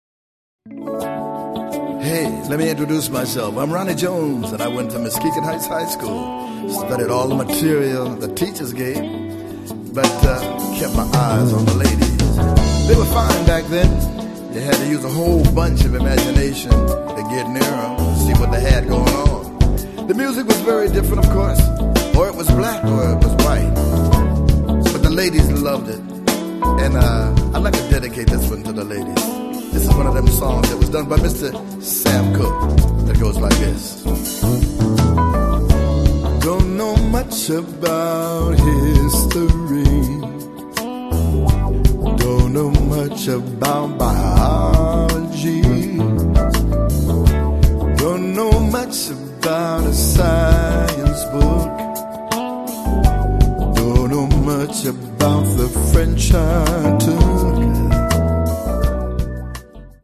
Un disco ballabile, anche ad occhi chiusi.